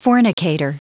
Prononciation du mot fornicator en anglais (fichier audio)
Prononciation du mot : fornicator